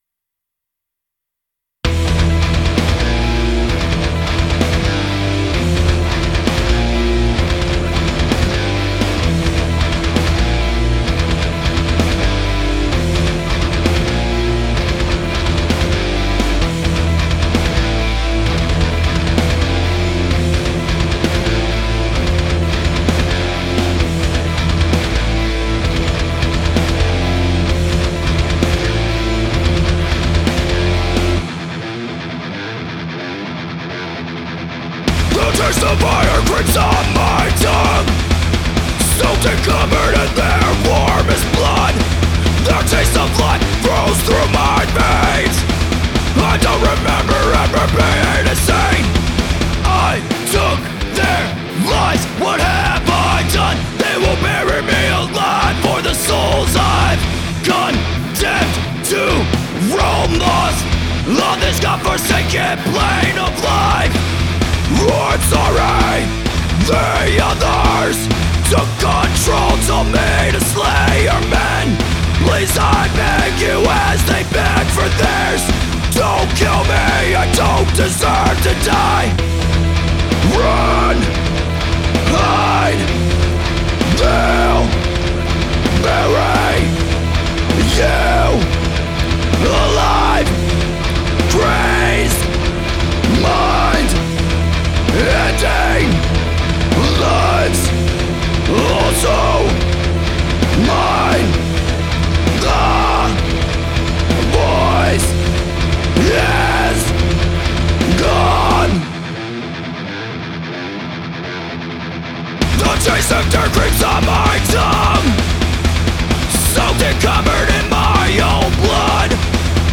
Genre: heavymetal, metal.